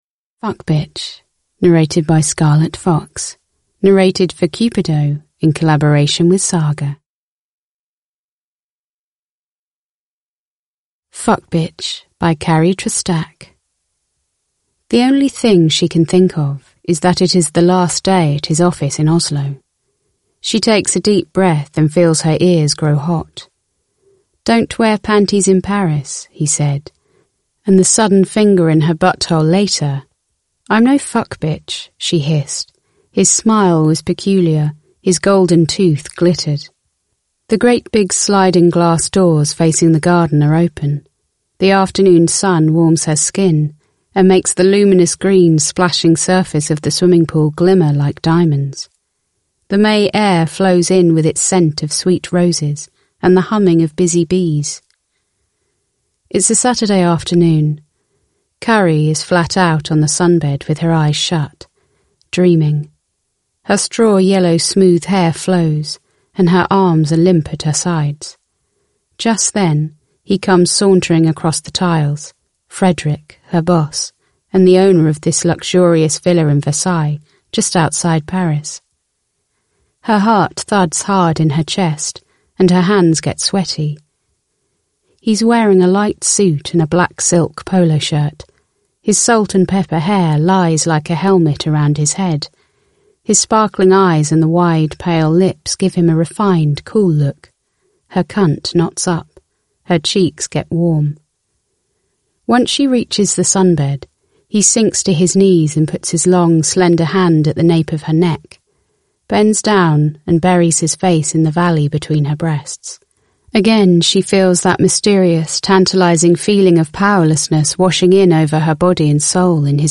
Fuckbitch (ljudbok) av Cupido